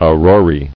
[au·ro·rae]